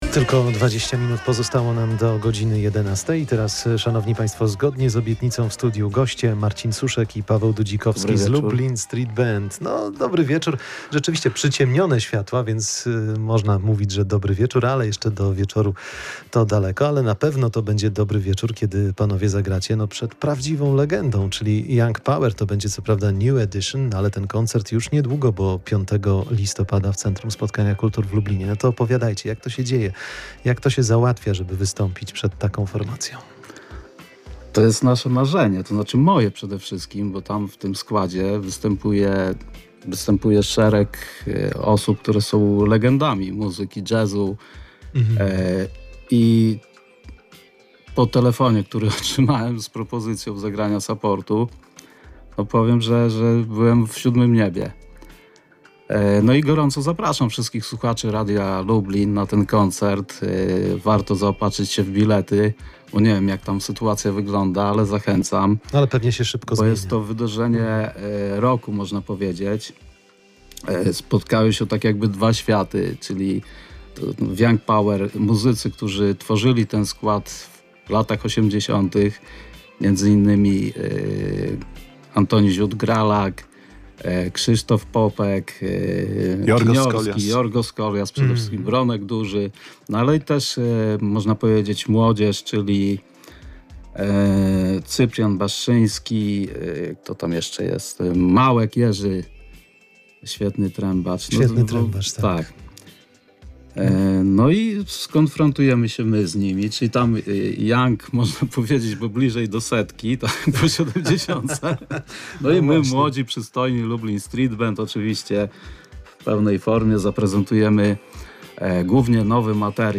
Lublin Street Band występuje z legendą i tworzy drugą płytę [POSŁUCHAJ ROZMOWY]